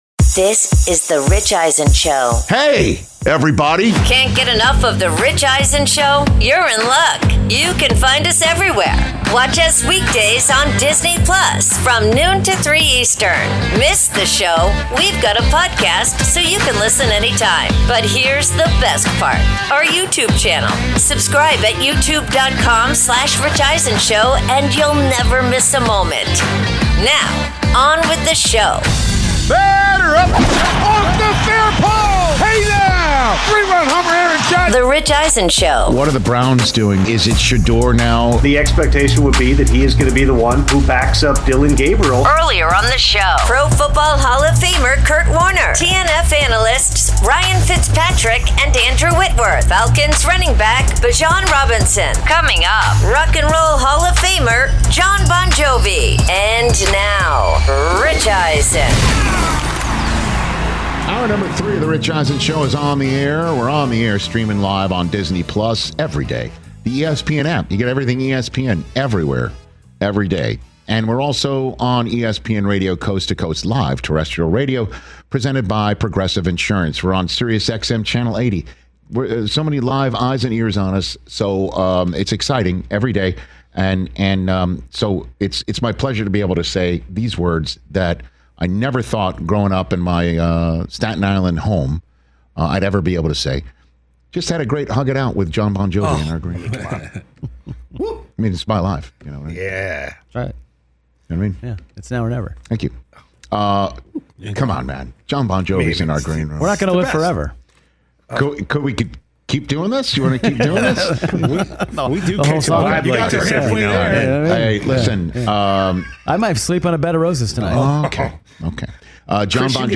Hour 3: NFL Week 6 Power Rankings, plus Jon Bon Jovi In-Studio
Rich breaks down the intrigue surrounding the Philadelphia Eagles heading into their Thursday Night Football NFC East showdown against the New York Giants, and reveals his brand-new NFL Power Rankings with a new #1 team. Rock & Roll Hall of Famer Jon Bon Jovi joins Rich in-studio to discuss his new album, the origins of his New England Patriots fandom back in the Bill Parcells era, reveals how fans react when they spot him and Bruce Springsteen driving around together in their native New Jersey, and much more.